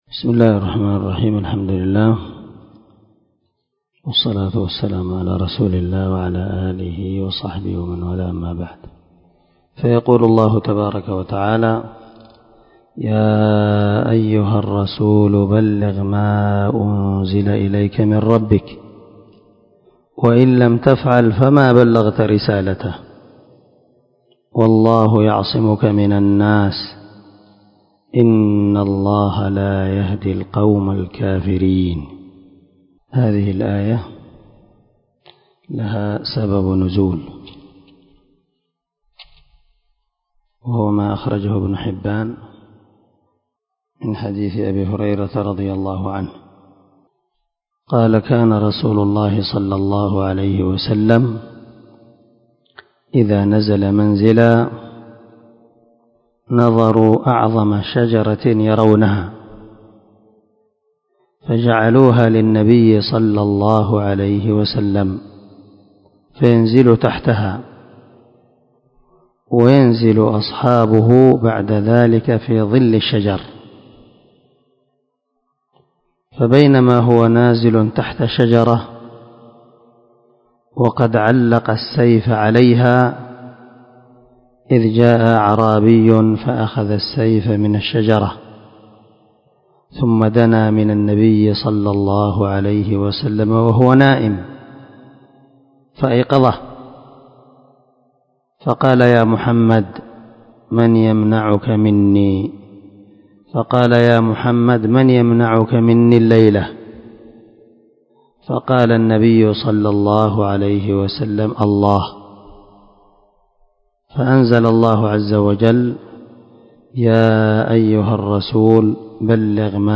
375الدرس 41 تفسير آية ( 67 – 68 ) من سورة المائدة من تفسير القران الكريم مع قراءة لتفسير السعدي
دار الحديث- المَحاوِلة- الصبيحة.